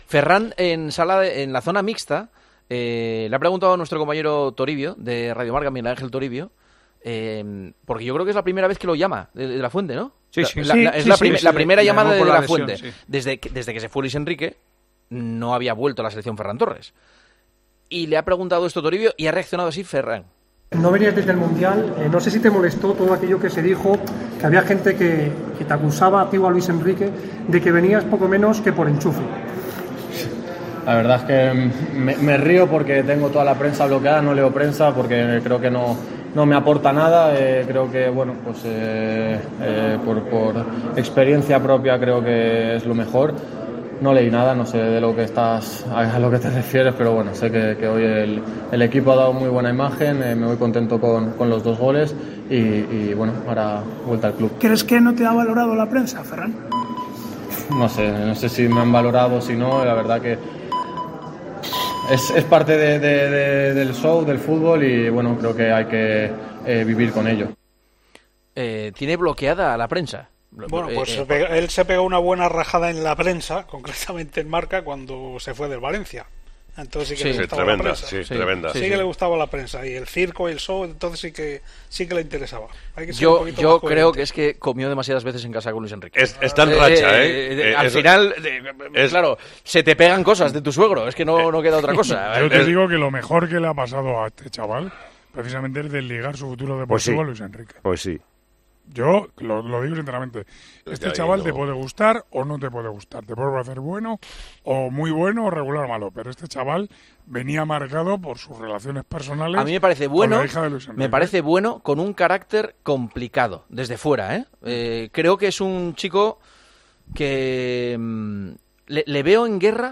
AUDIO: Juanma Castaño, Manolo Lama y Paco González valoran el estado de forma del futbolista convocado por De la Fuente tras las lesiones de Asensio y Dani Olmo.